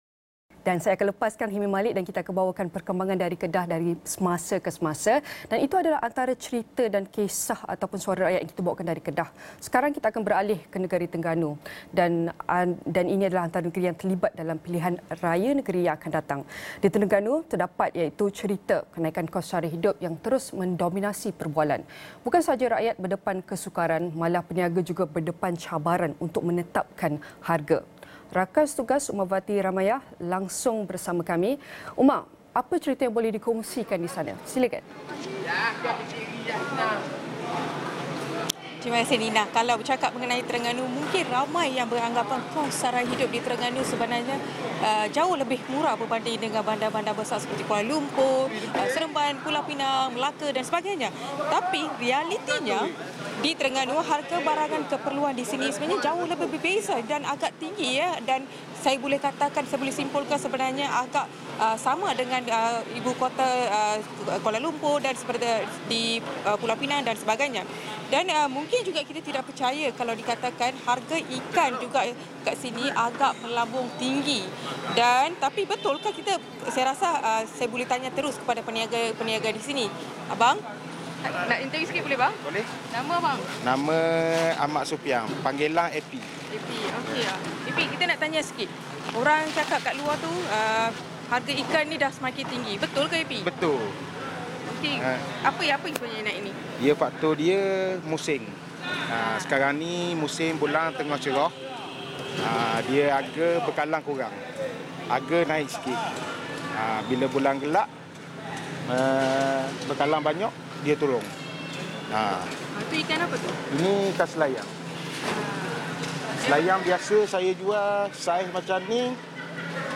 langsung bersama kami dari Pasar Payang, Kuala Terengganu.